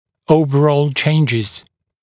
[‘əuvərɔːl ‘ʧeɪnʤɪz][‘оувэро:л ‘чейнджиз]общие изменения